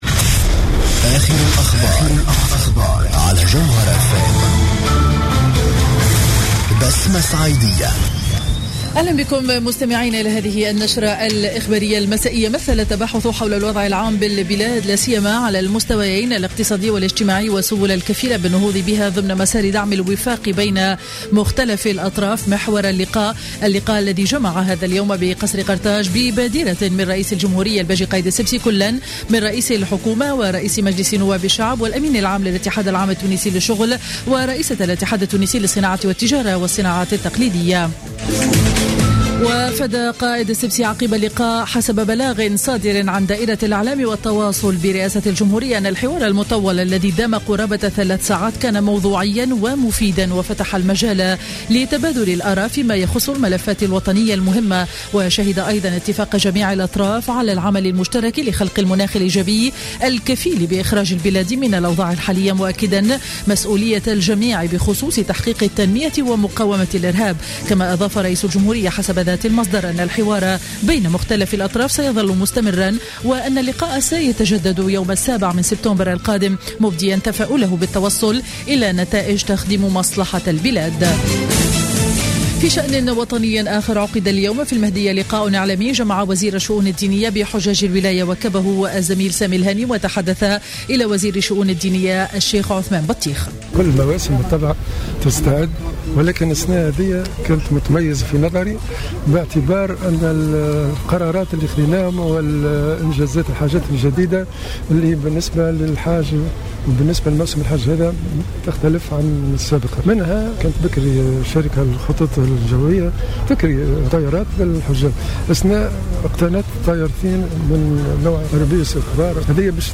نشرة اخبار السابعة مساء ليوم الثلاثاء 18 أوت 2015